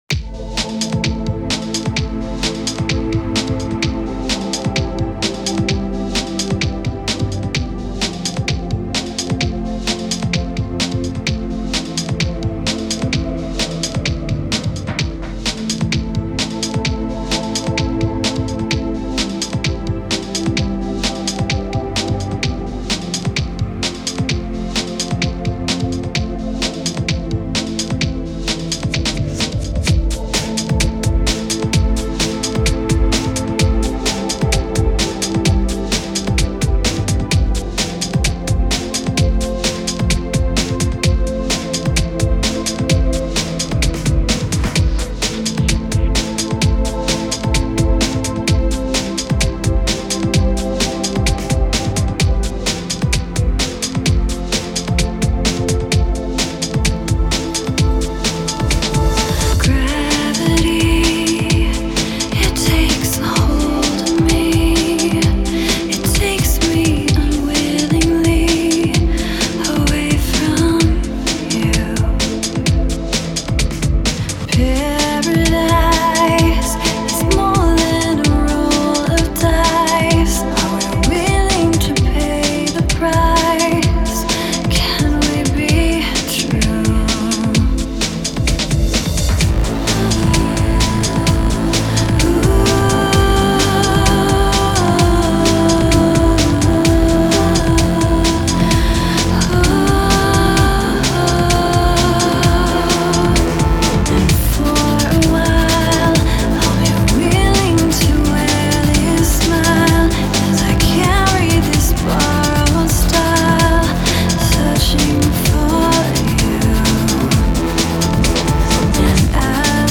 Транс Trance Транс музыка